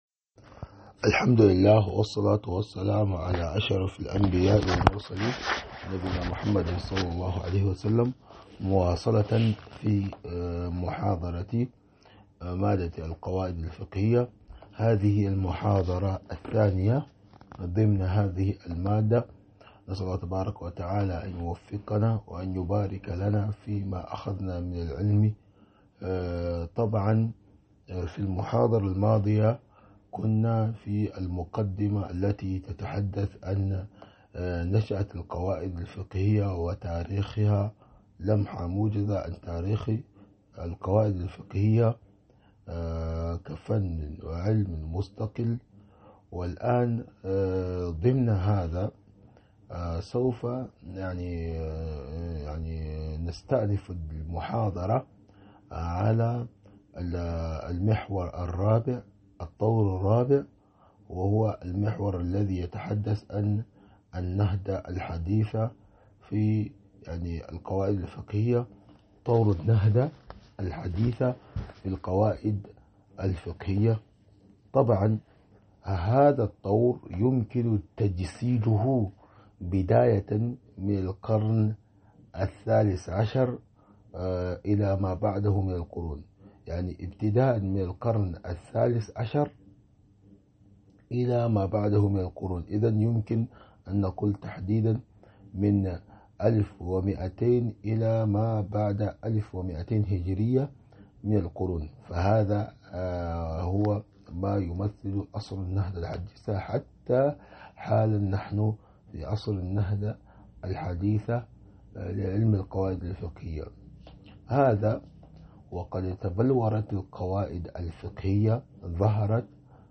محاضرة مادة القواعد الفقهية 002